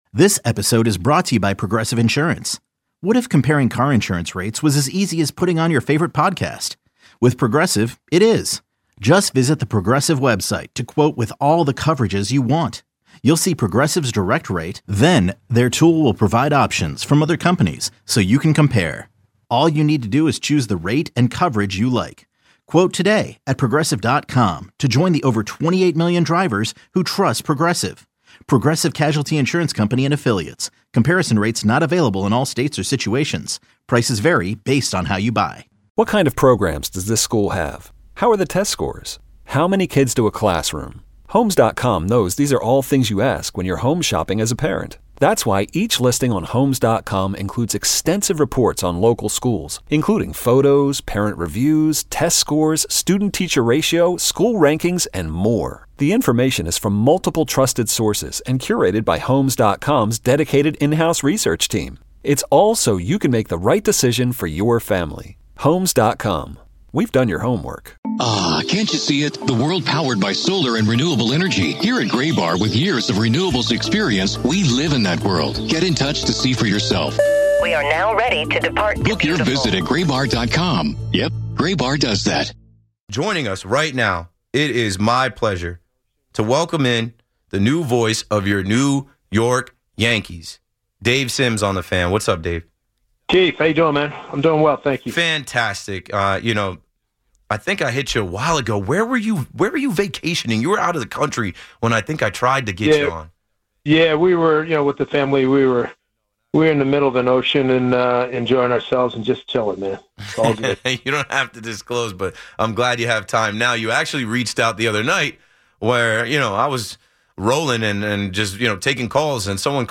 (INTERVIEW)